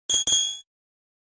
sonic exe teleport Meme Sound Effect
sonic exe teleport.mp3